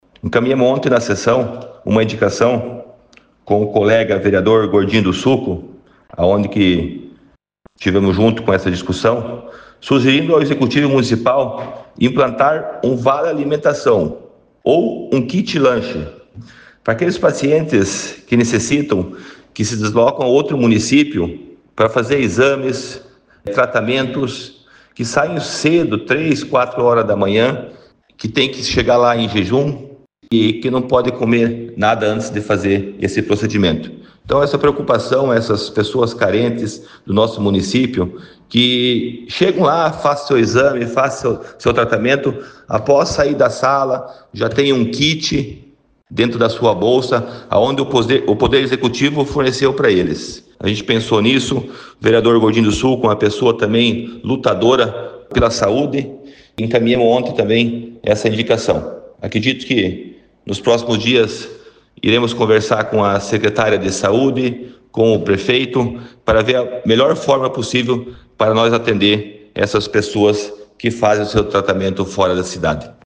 Ouça entrevistas dos vereadores, defendendo a proposta
O vereador Verde explica a proposta………